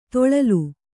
♪ toḷalu